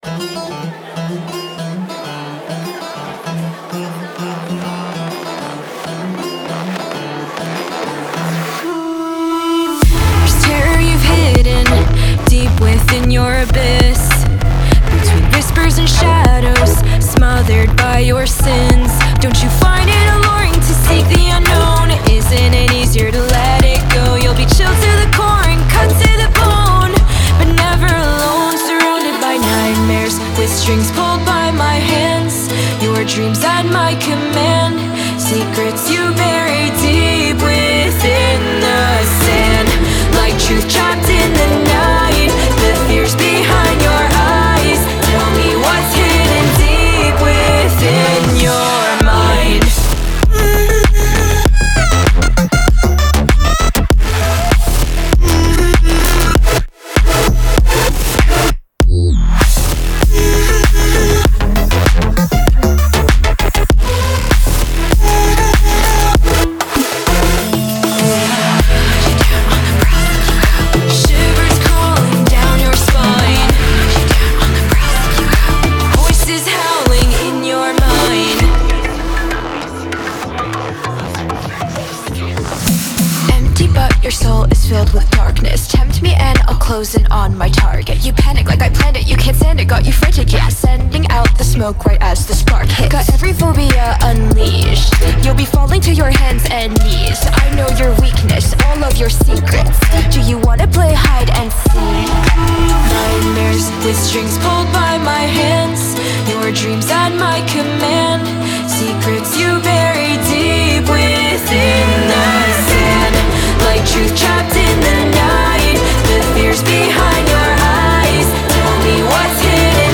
BPM98-98
Audio QualityPerfect (High Quality)
Dark Pop song for StepMania, ITGmania, Project Outfox
Full Length Song (not arcade length cut)